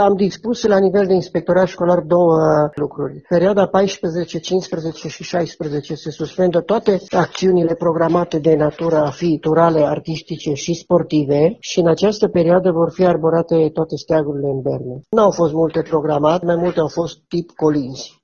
Inspectorul școlar general al județului Mureș, Ioan Macarie: